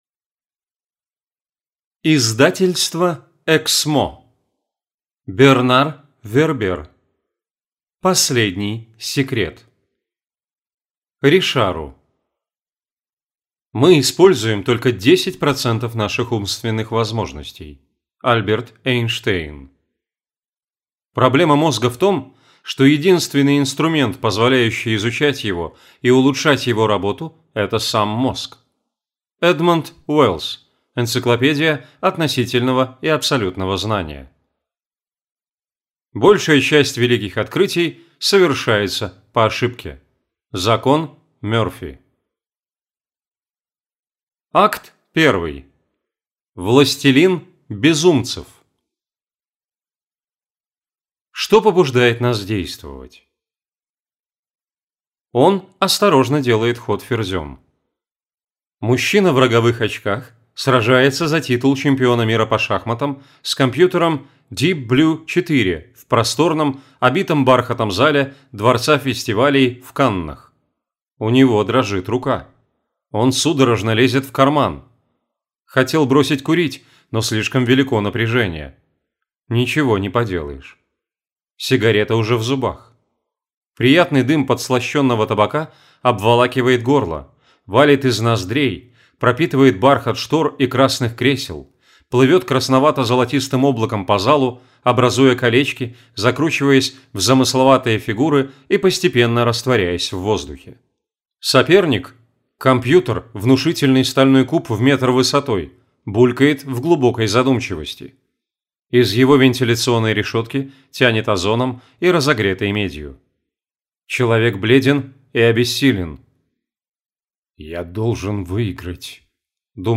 Аудиокнига Последний секрет | Библиотека аудиокниг